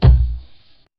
クラップ